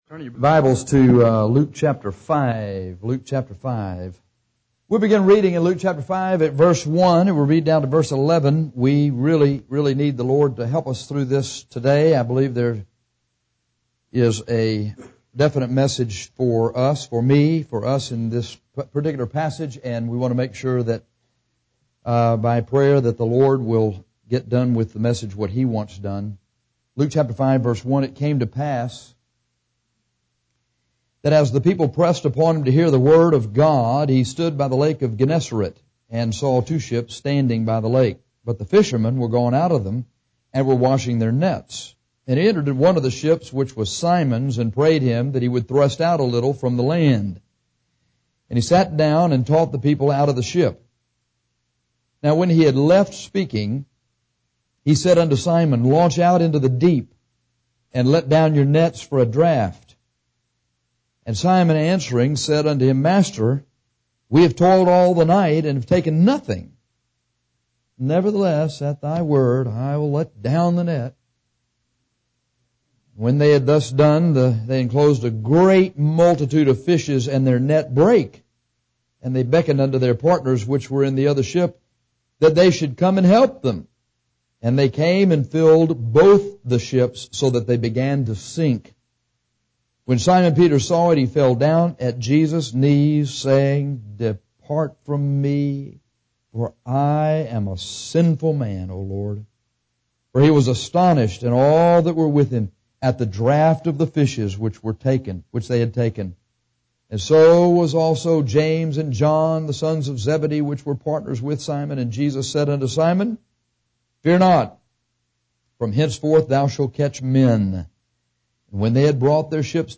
This sermon on fishers on men is very inspirational and gets you to see what we need to do together to be able to "catch" the souls of men for Christ.